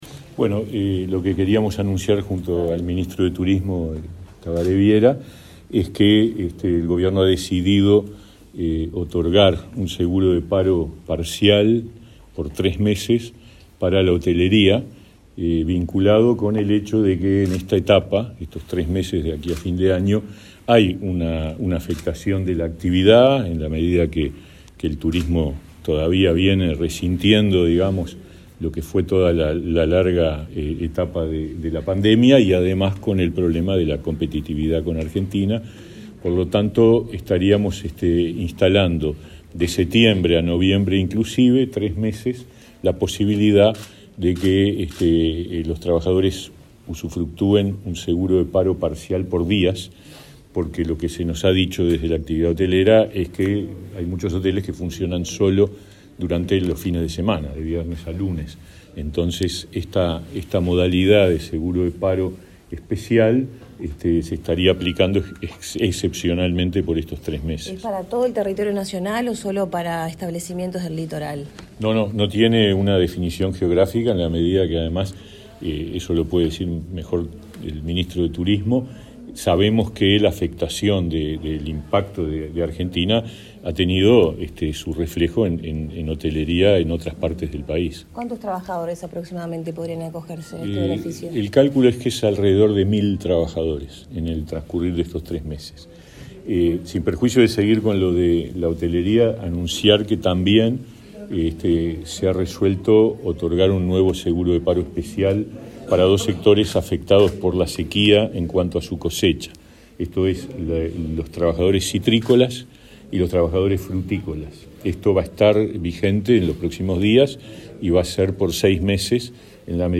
Declaraciones de los ministros de Trabajo y Turismo
Declaraciones de los ministros de Trabajo y Turismo 18/09/2023 Compartir Facebook X Copiar enlace WhatsApp LinkedIn El ministro de Trabajo, Pablo Mieres, y su par de Turismo, Tabaré Viera, informaron a la prensa sobre la decisión del Gobierno de otorgar un seguro de paro parcial a los trabajadores del sector hotelero.